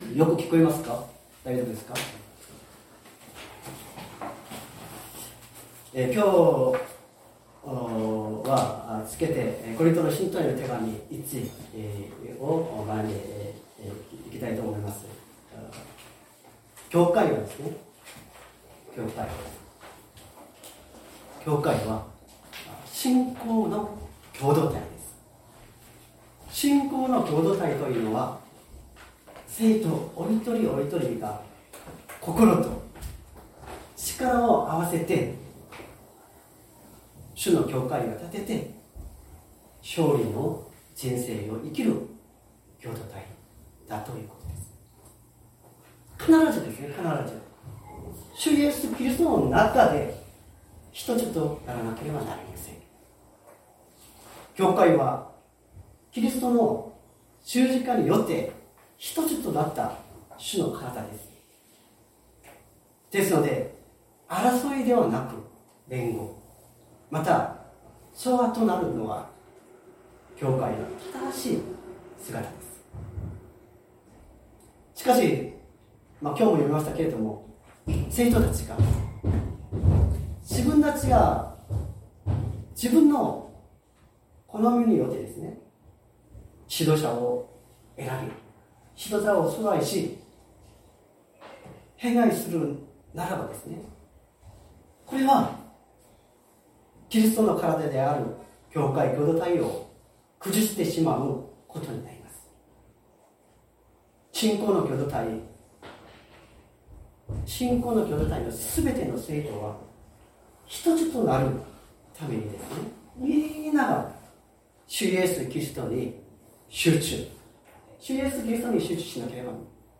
日曜朝の礼拝
礼拝説教を録音した音声ファイルを公開しています。